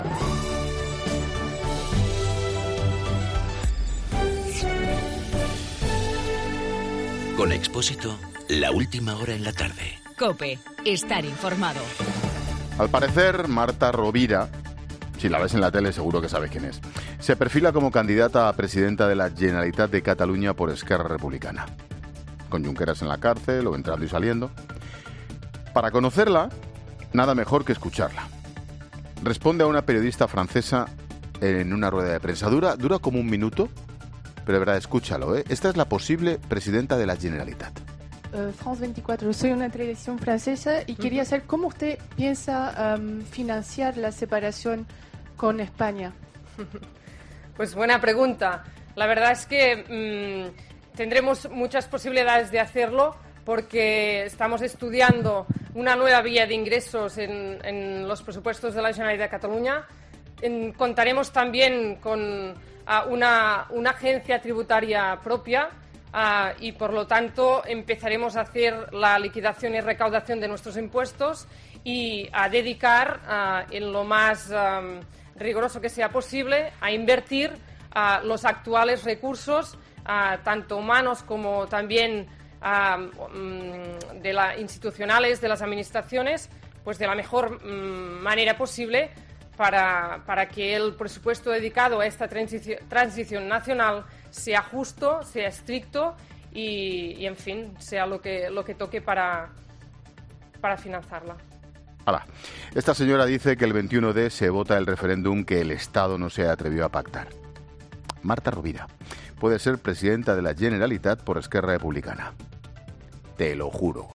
Monólogo de Expósito
El comentaro de Ángel Expósito sobre la candidata por ERC a las elecciones en Cataluña.